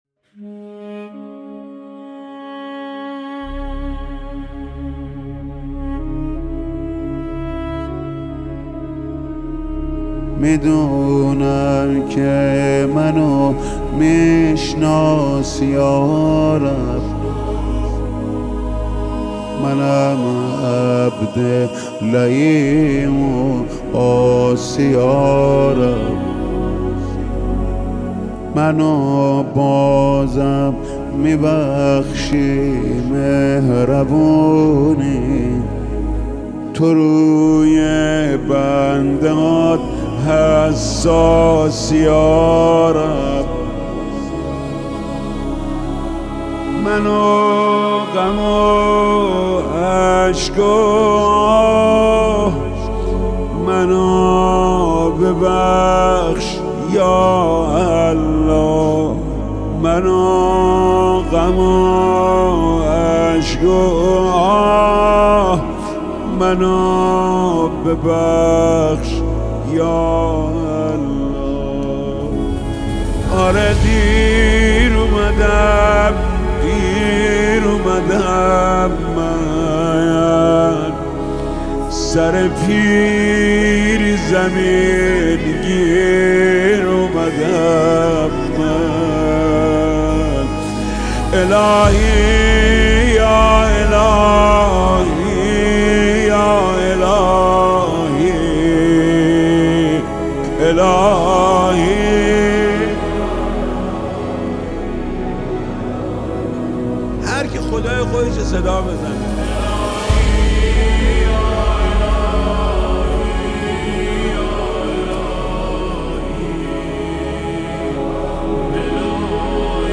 دل‌انگیز و معنوی